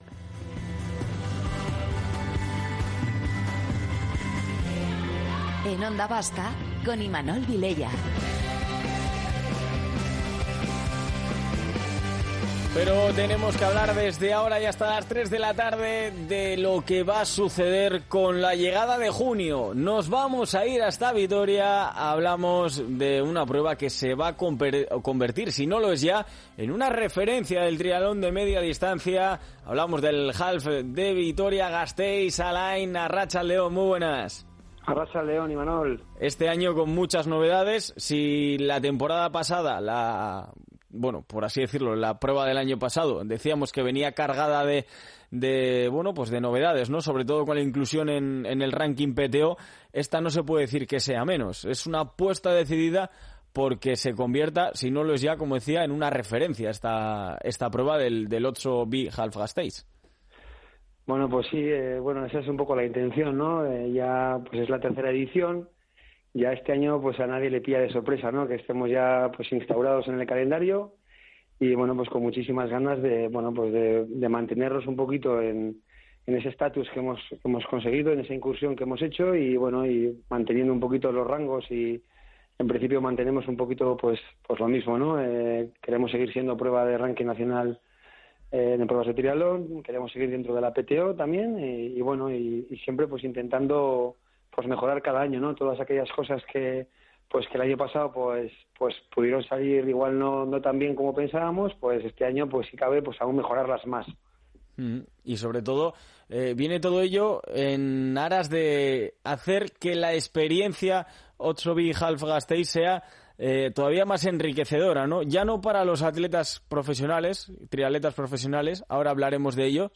Entrevistas deportivas